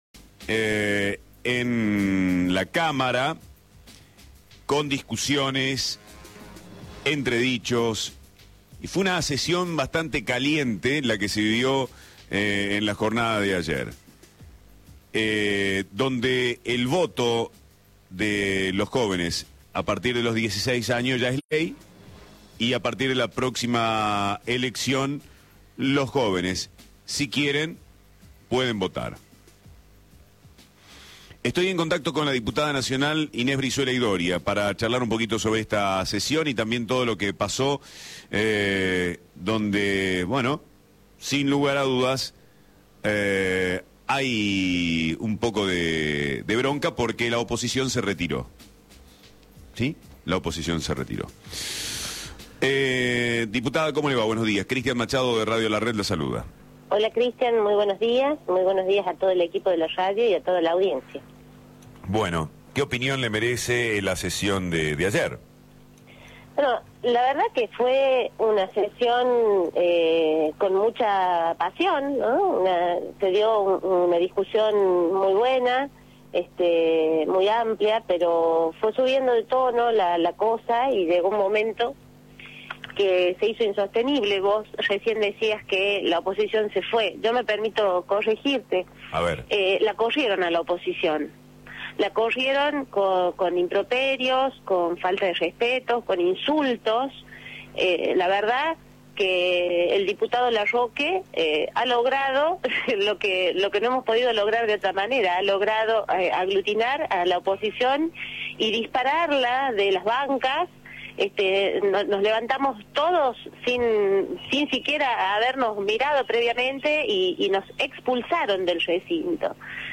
Inés Brizuela y Doria, diputada nacional, por Radio La Red
inc3a9s-brizuela-y-doria-diputada-nacional-por-radio-la-red.mp3